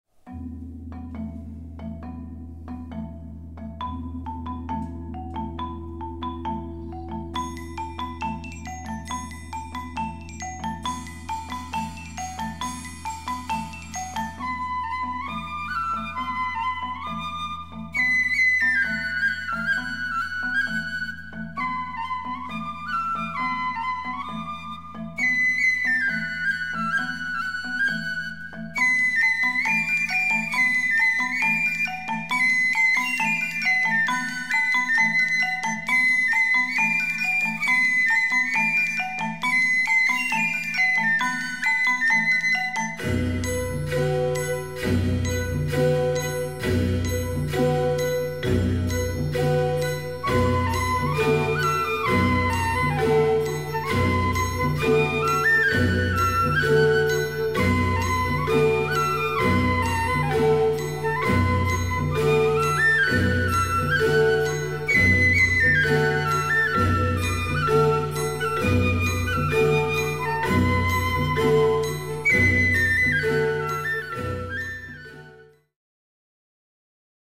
3:00 Minuten Besetzung: Blasorchester Zu hören auf